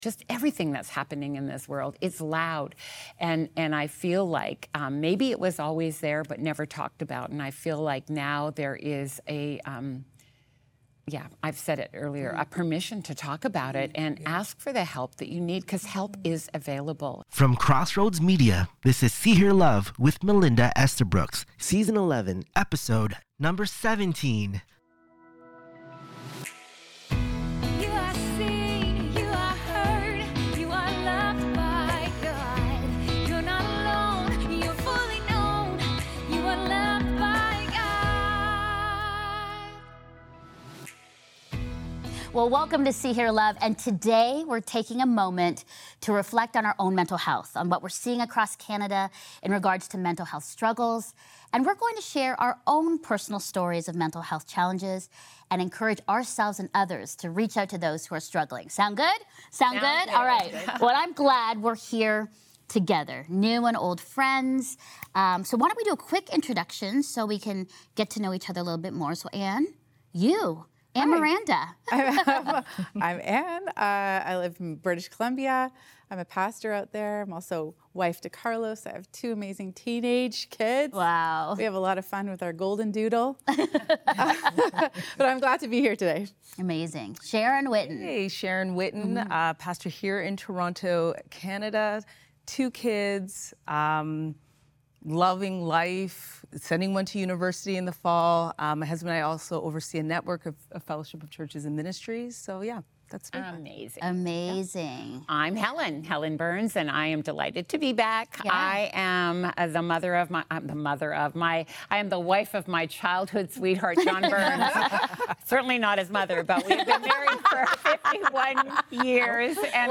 From post-COVID trauma to physical breakdowns and the healing power of community, this conversation offers hope, wisdom, and practical encouragement for anyone feeling overwhelmed.